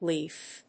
発音記号
• / líːf(米国英語)